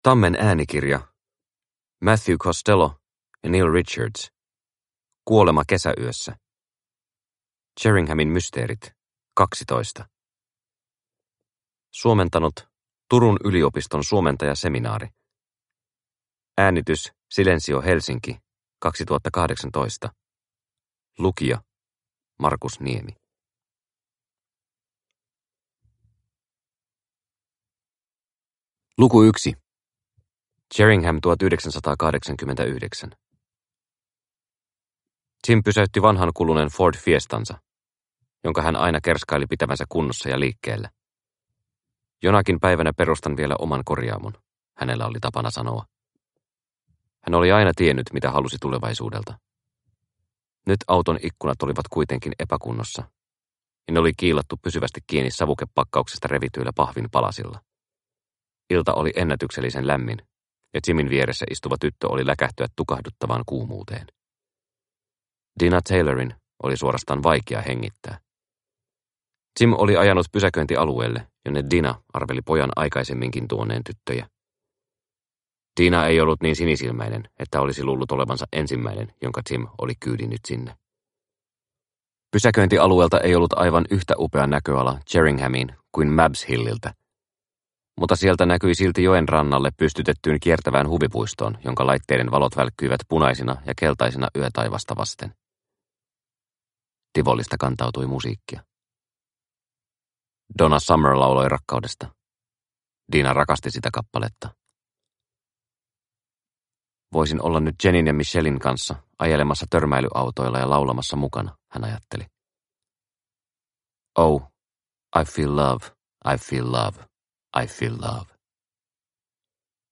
Kuolema kesäyössä – Ljudbok – Laddas ner